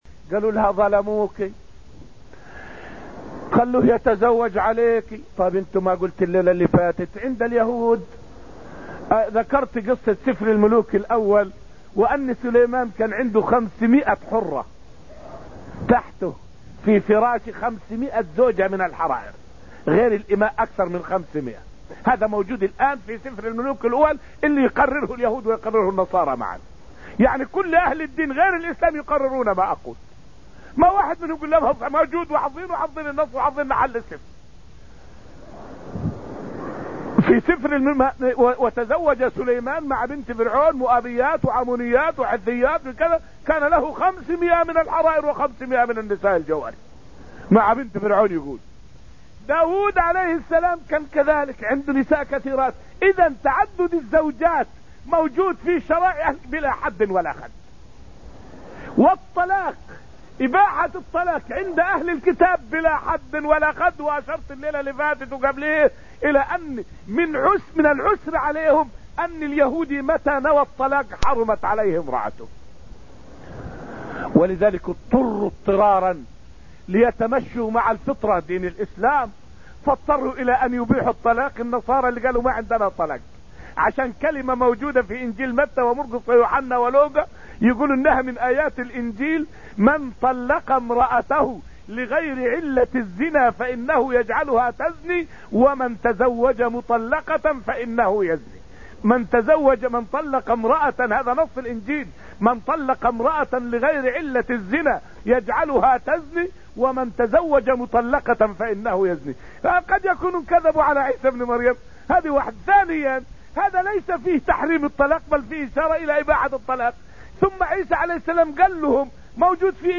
فائدة من الدرس الرابع عشر من دروس تفسير سورة الحديد والتي ألقيت في المسجد النبوي الشريف حول حكم تعدد الزوجات والطلاق في الإسلام.